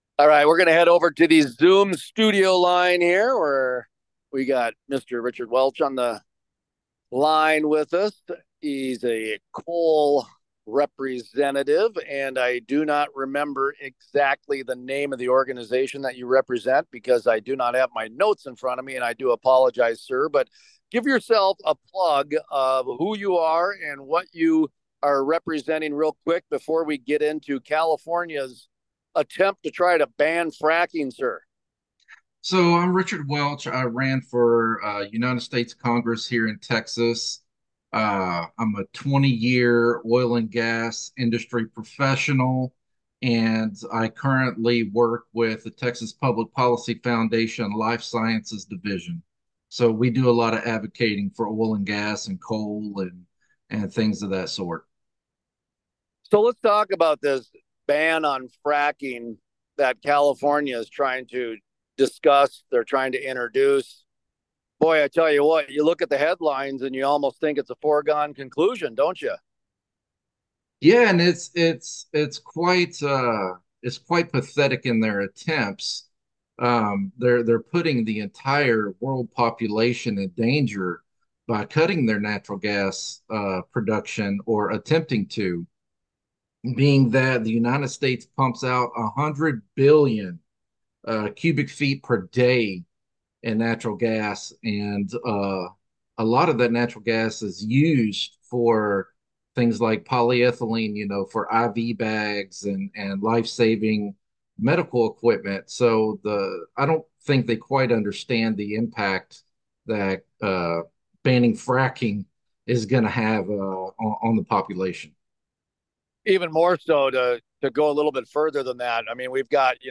Full Length Interviews Is the Energy Transition Already Done in the Oil and Gas Industry?